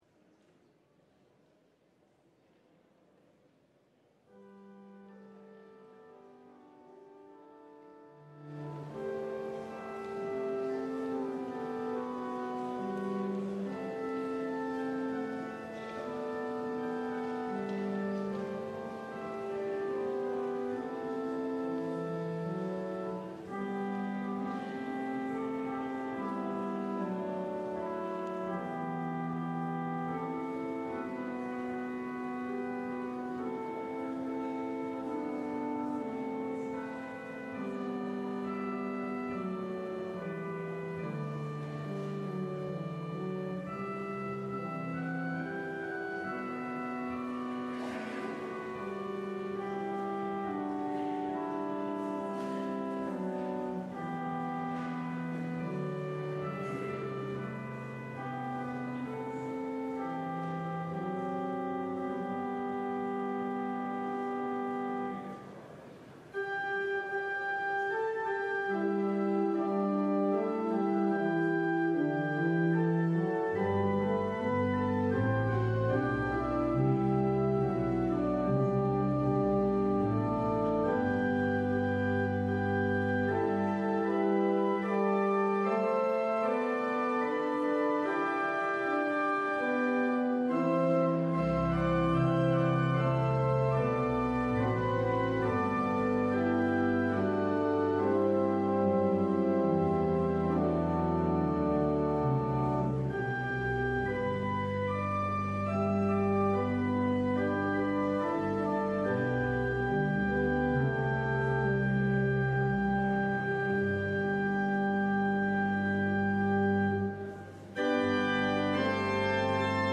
LIVE Morning Worship Service - Looking to Jesus